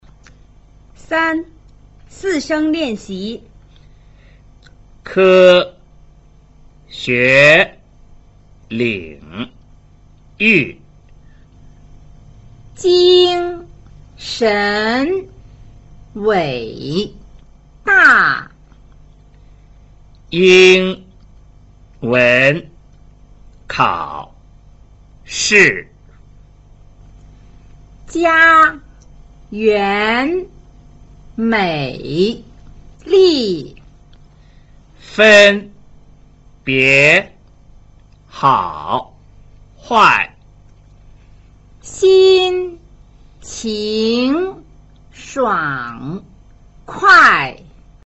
(3) 四聲練習